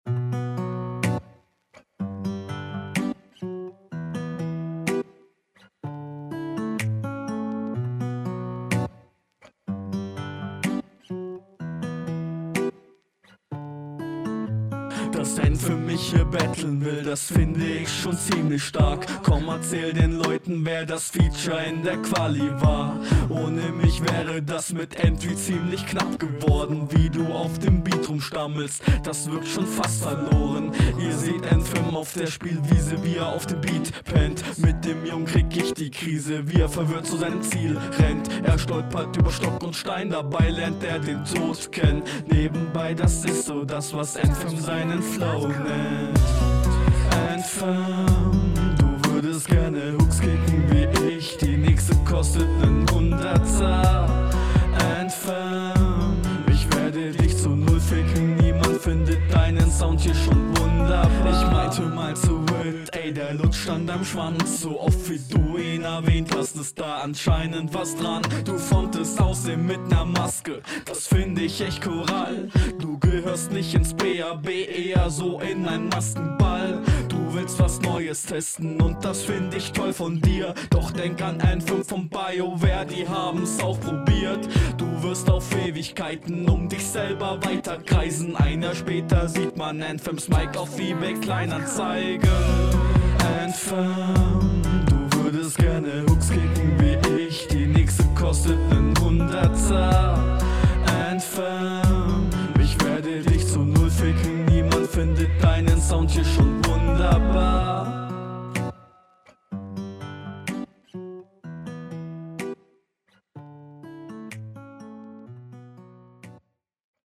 Ich sags mal direkt, ich mag die Hook.